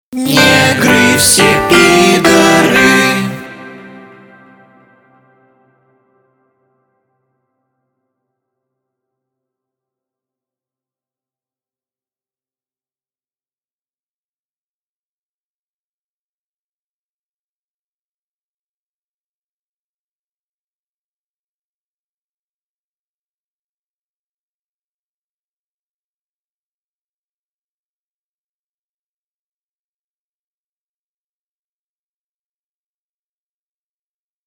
• Category: Sounds of the store "Pyaterochka"
• Quality: High